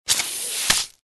Звуки Человека-Паука, паутины
Паутина человека паука мчится